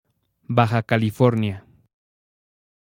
2. ^ Spanish pronunciation: [ˈbaxa kaliˈfoɾnja]
BajaCalifornia.ogg.mp3